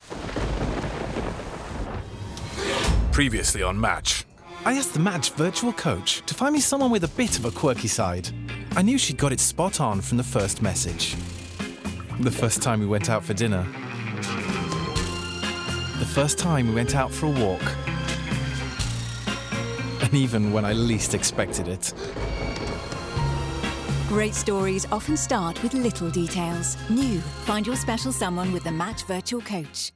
Bandes-son
Voix off anglais - publicité meetic